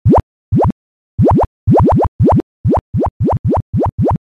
Звуки пузырьков
На этой странице собраны разнообразные звуки пузырьков: от легкого бульканья до интенсивного шипения газировки.